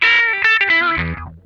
CRUNCH LICK2.wav